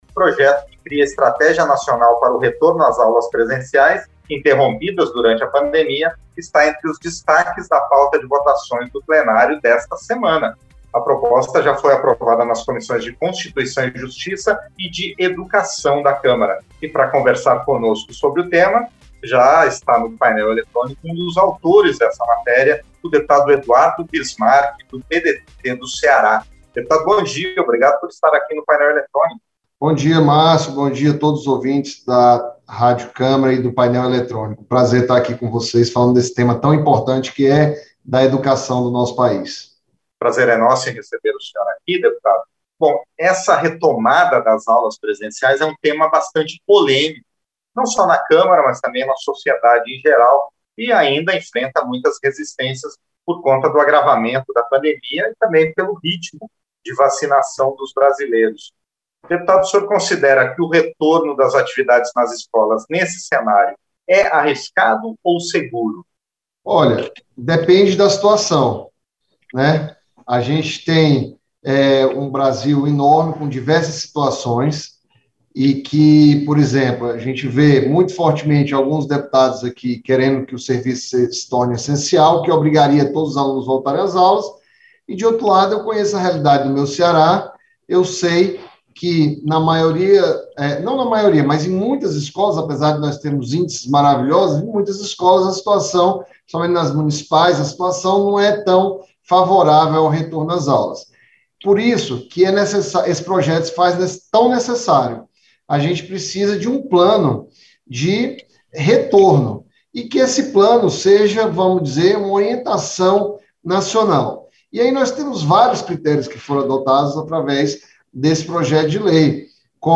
Entrevista - Dep. Eduardo Bismarck (PDT-CE)